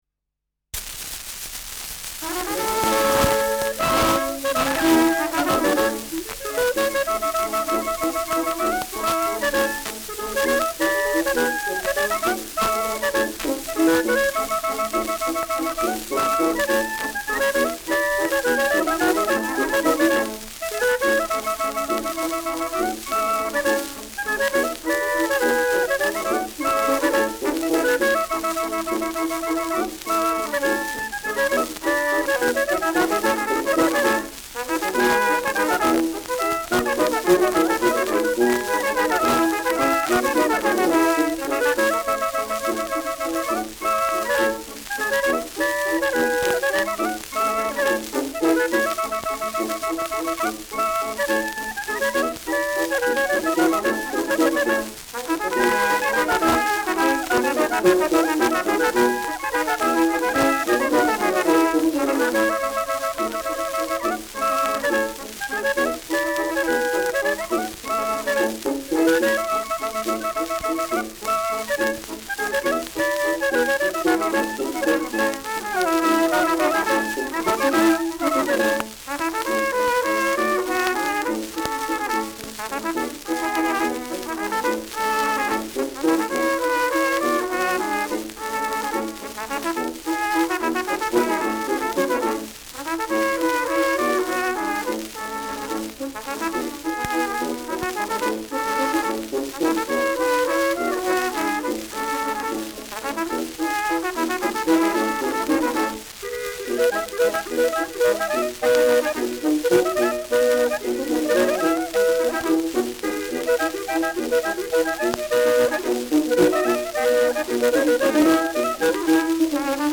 Im Hochgebirge : Ländler
Schellackplatte
präsentes Rauschen
Kapelle Pokorny, Bischofshofen (Interpretation)